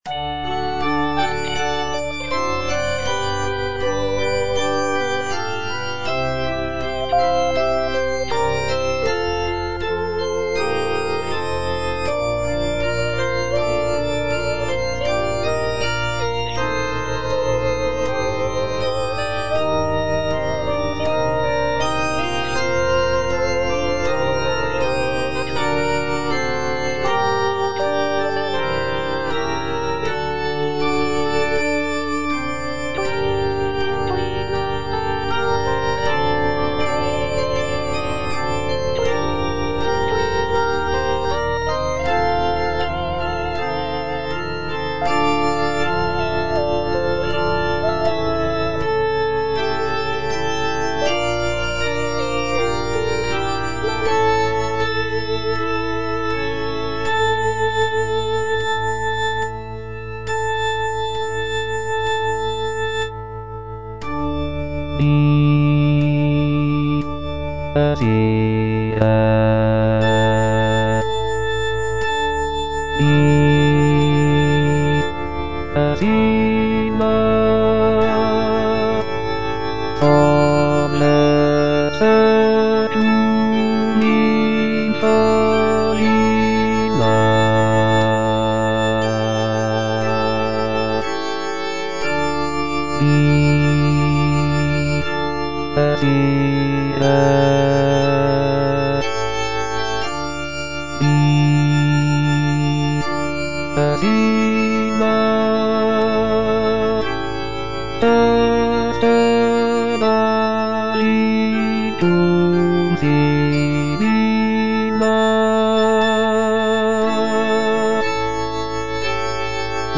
Bass (Voice with metronome) Ads stop